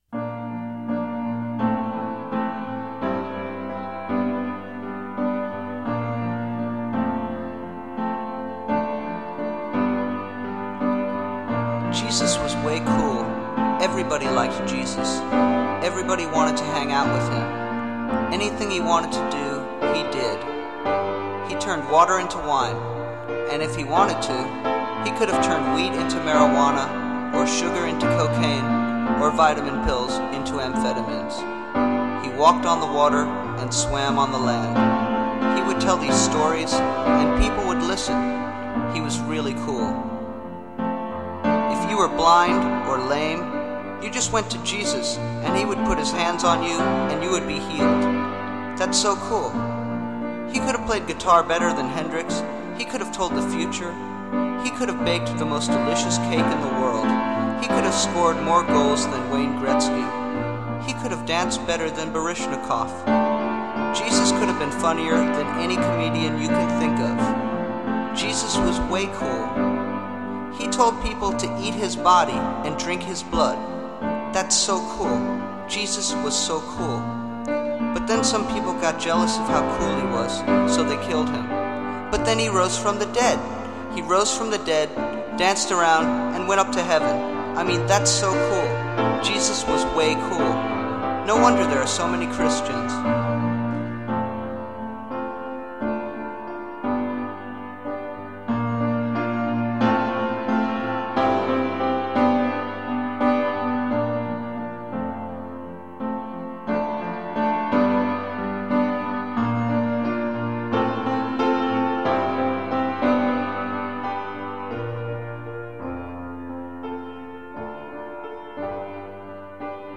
droll, mostly spoken word narratives